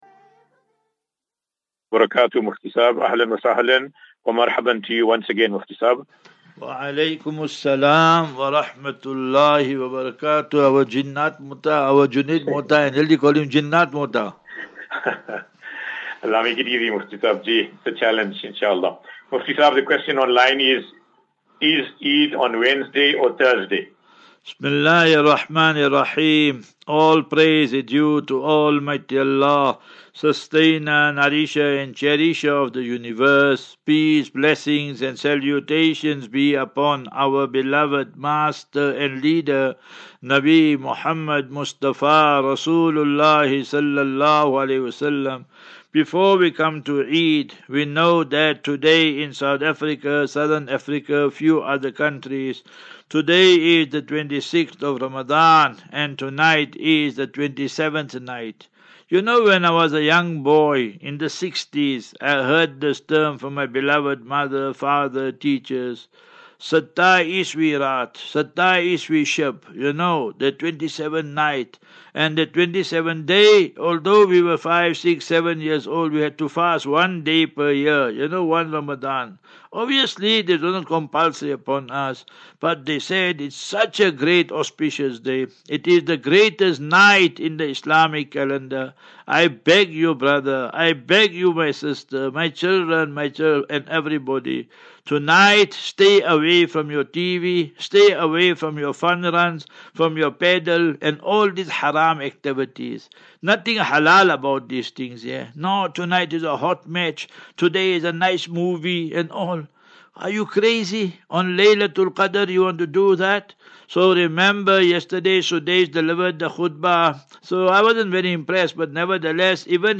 View Promo Continue Install As Safinatu Ilal Jannah Naseeha and Q and A 6 Apr 06 April 2024.